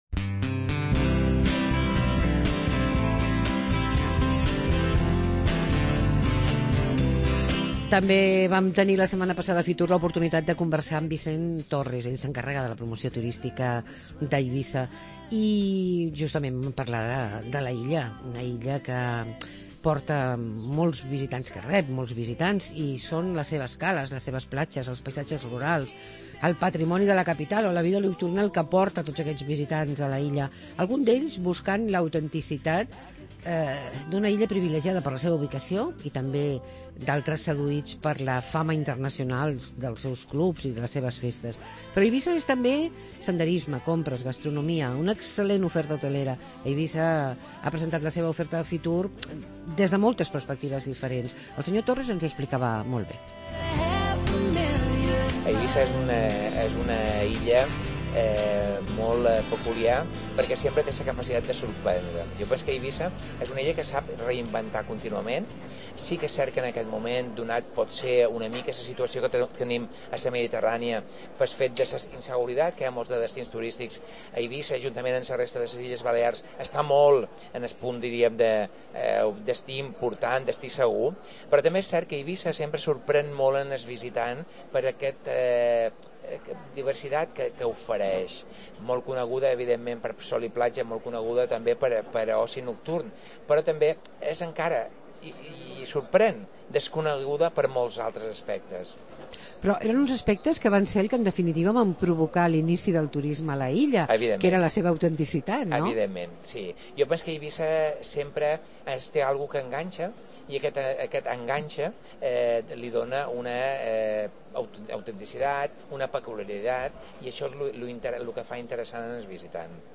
Entrevista a Vicent Torres, director insular de Turismo de Ibiza